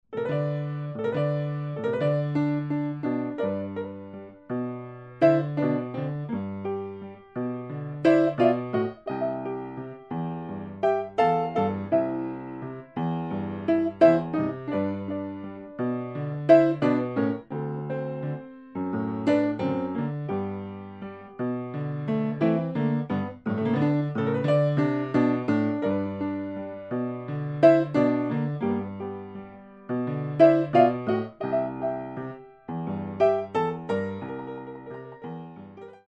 Style: New Orleans Piano
Bei ihm flossen auch südamerikanische Rhythmen mit ein.